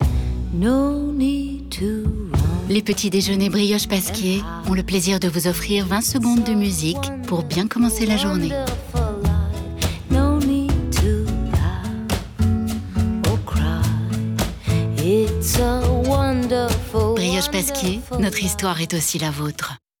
message radio publicitaire